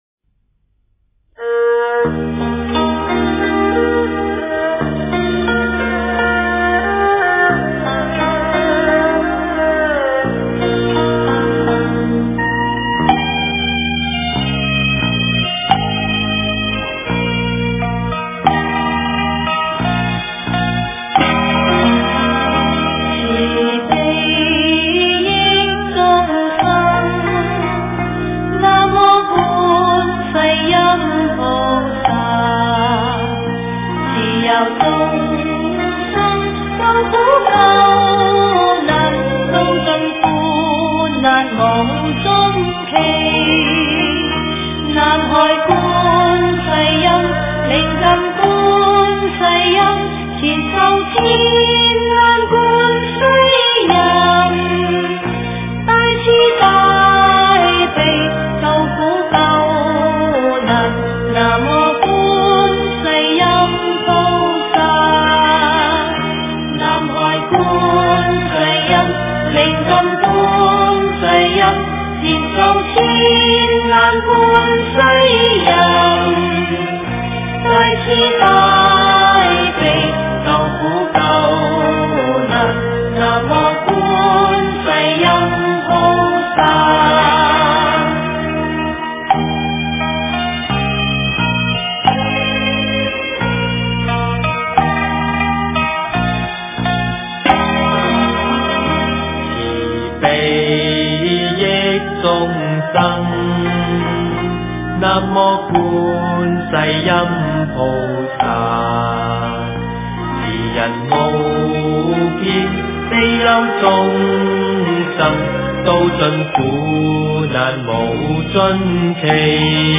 慈佑众生观世音 诵经 慈佑众生观世音--佛教音乐 点我： 标签: 佛音 诵经 佛教音乐 返回列表 上一篇： 稽山小颂 下一篇： 山景 相关文章 唵嘛呢叭咪嗡 Om Mani Padme Om--禅定音乐 唵嘛呢叭咪嗡 Om Mani Padme Om--禅定音乐...